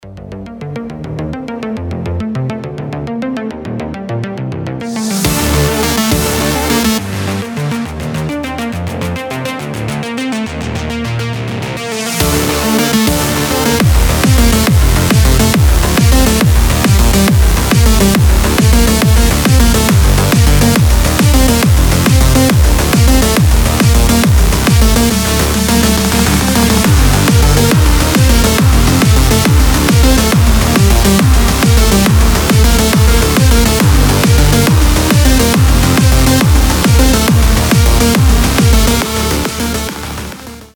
Энергичный транс - рингтон